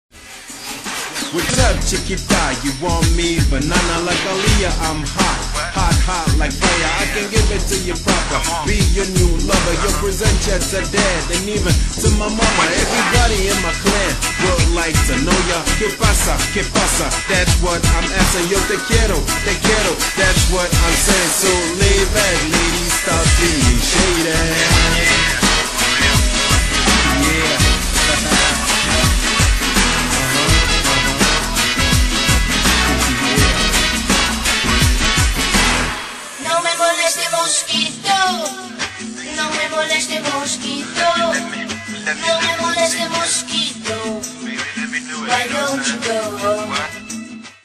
Spanish-Mix